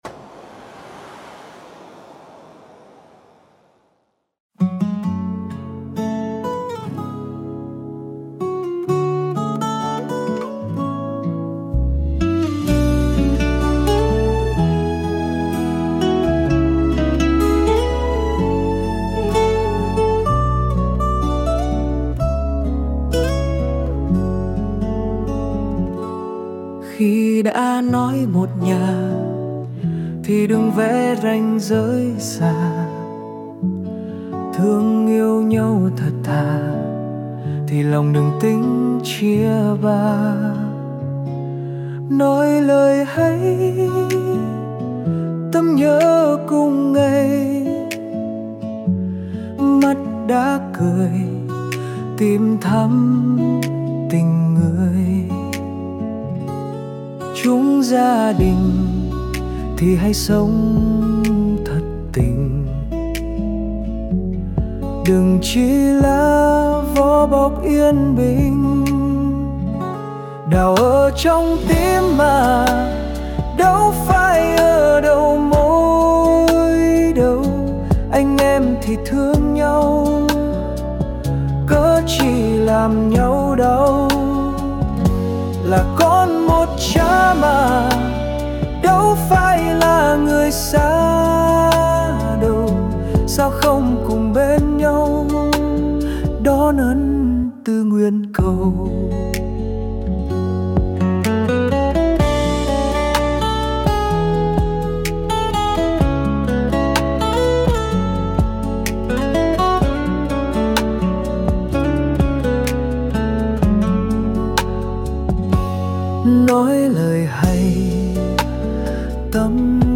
Nhạc AI